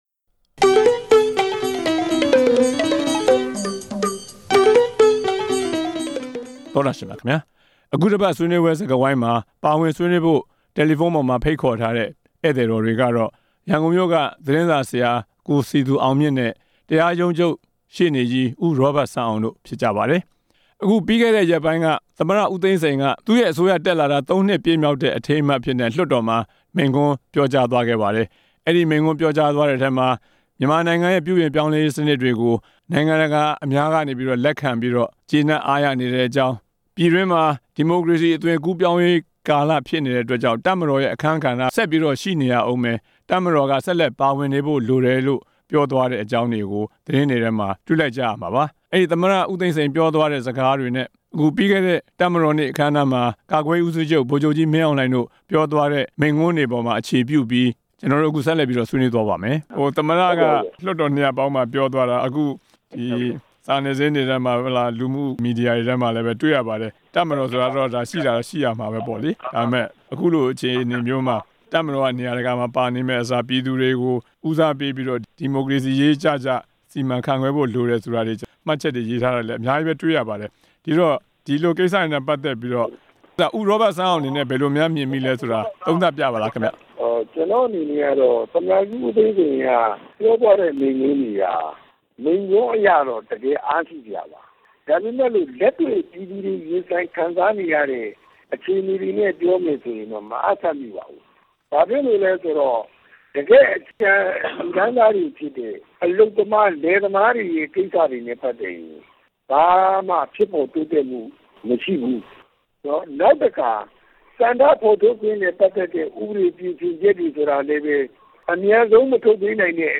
သမ္မတ ဦးသိန်းစိန်ရဲ့ လွှတ်တော်မှာ ပြောခဲ့တဲ့မိန့်ခွန်း ဆွေးနွေးချက်